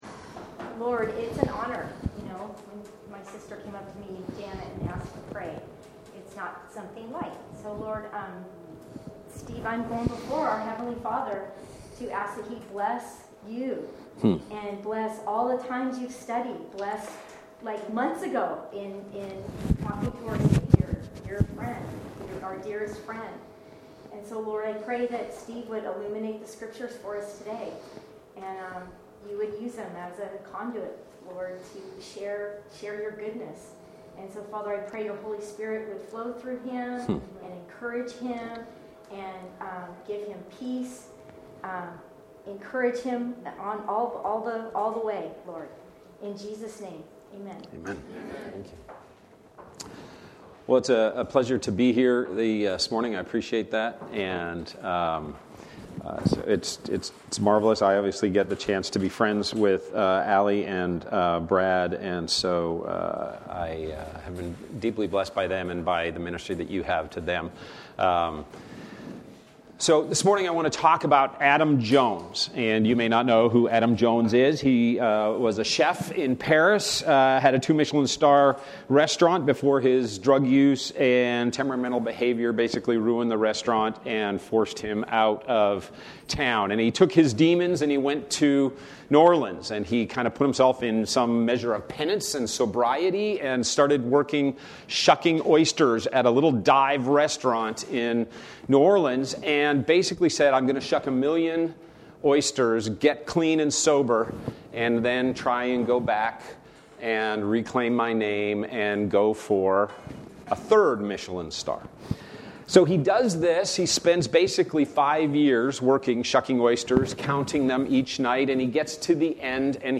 Genesis Passage: Genesis 1:26-28, Genesis 2:18-25, Genesis 11:1-9 Service Type: Sunday Morning